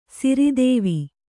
♪ siri dēvi